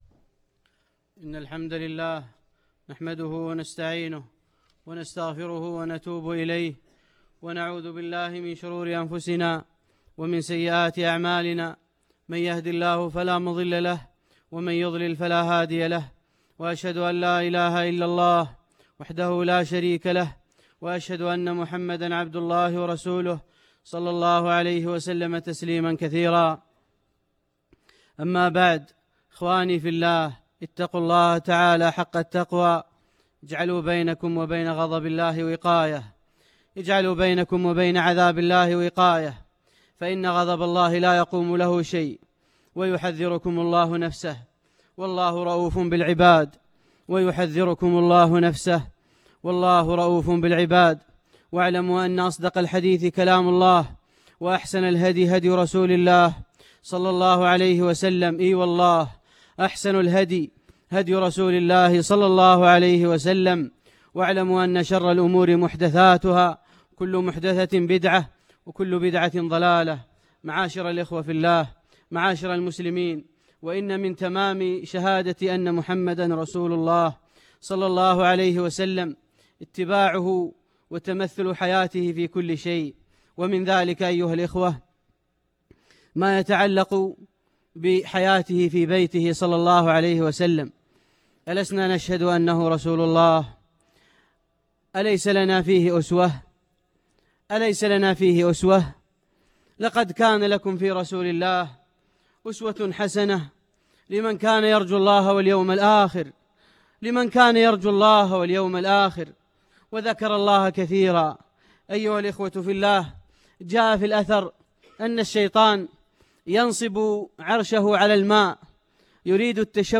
خطبة الجمعة بعنوان رفقاً بالقوارير